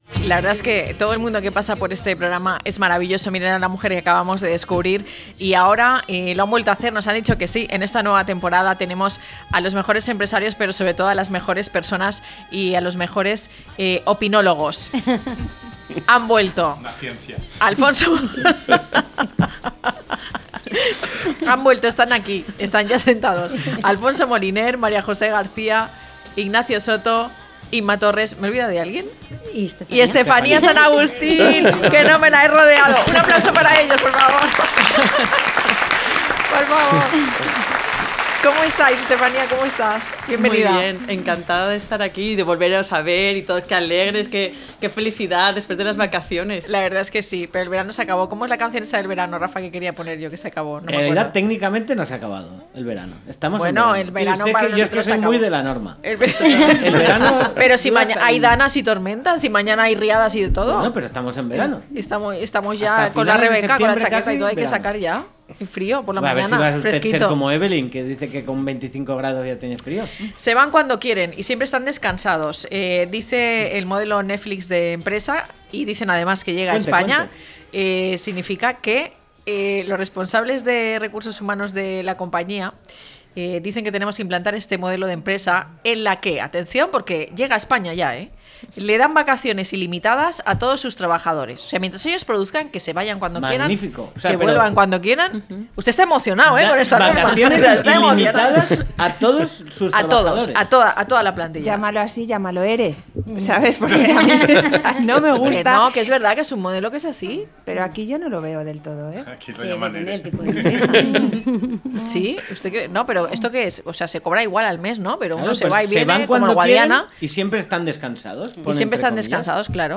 0903-LTCM-TERTULIA.mp3